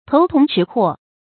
頭童齒豁 注音： ㄊㄡˊ ㄊㄨㄙˊ ㄔㄧˇ ㄏㄨㄛˋ 讀音讀法： 意思解釋： 童：原指山無草木，比喻人禿頂；豁：缺口。